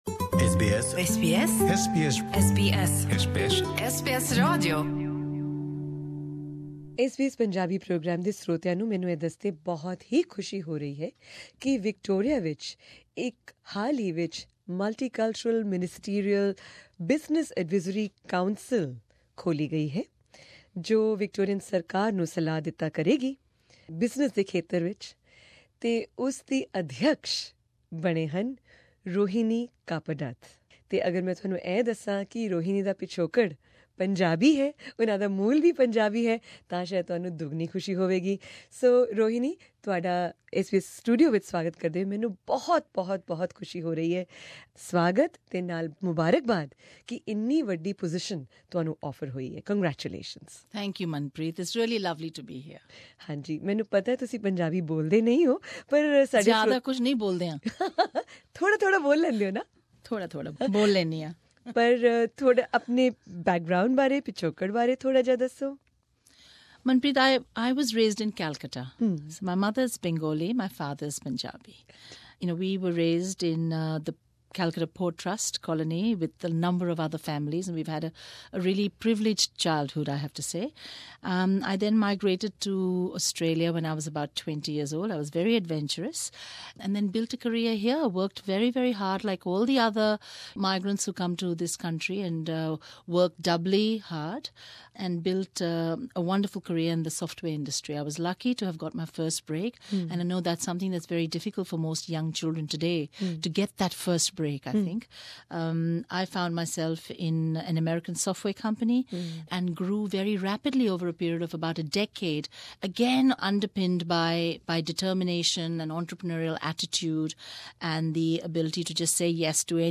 The Council begins work this month and Ms Kappadath tells us more about it, in this interview Share